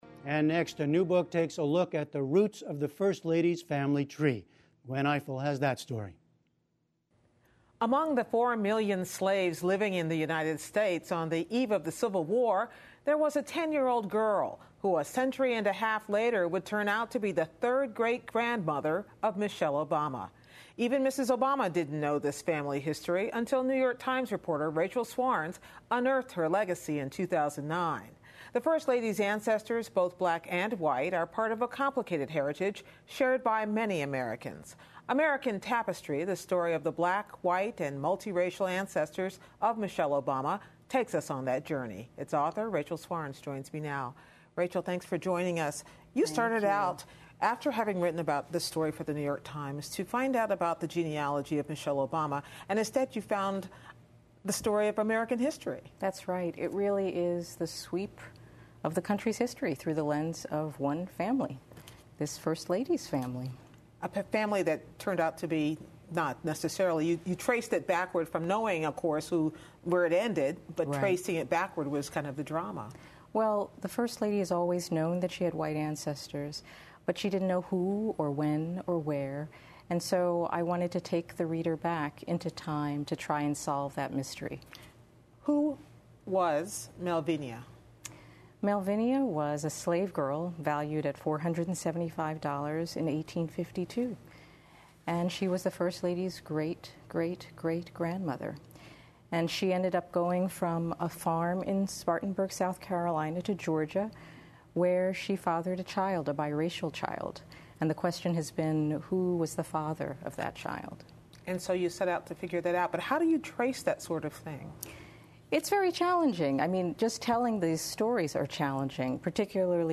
英语访谈节目:第一夫人米歇尔·奥巴马的美国血统